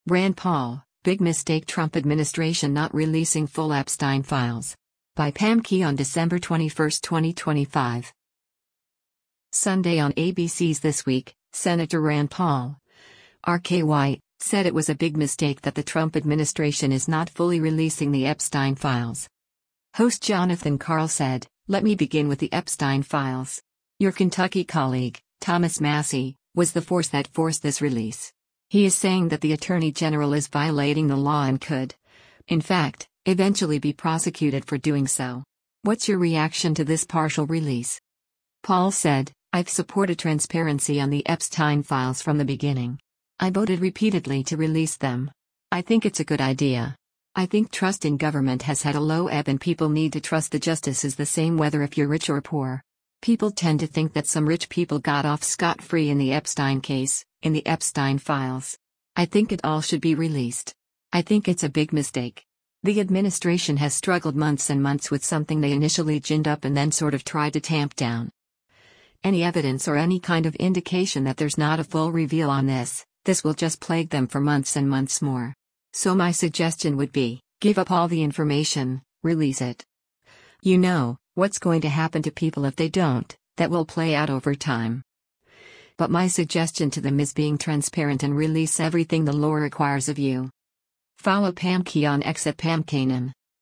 Sunday on ABC’s “This Week,” Sen. Rand Paul (R-KY) said it was a “big mistake” that the Trump administration is not fully releasing the Epstein files.